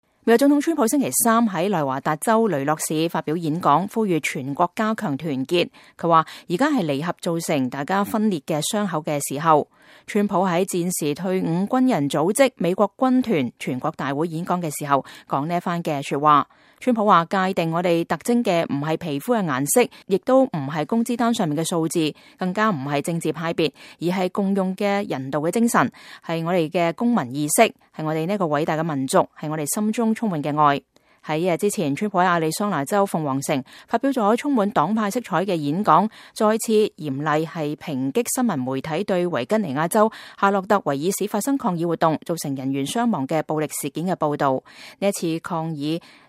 川普內華達演講：呼籲妥協，加強團結